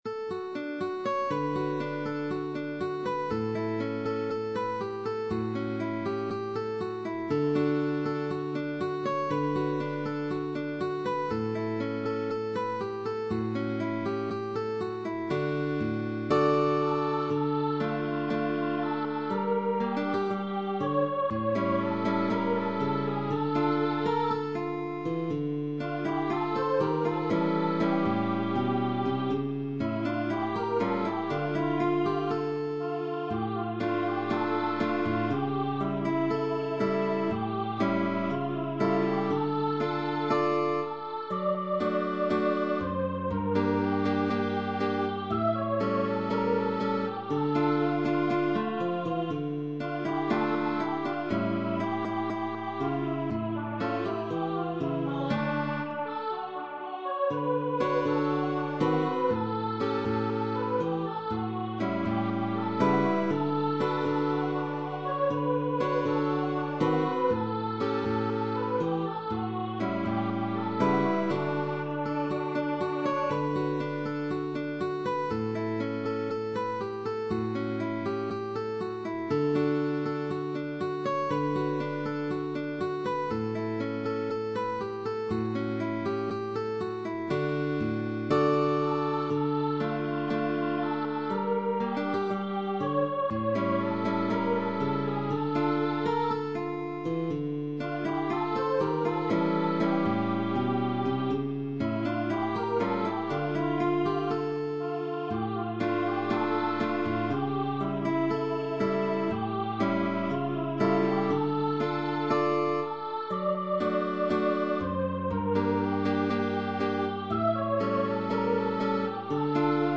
Voice & GUITAR Tango Milonga (Argentinian melodic song).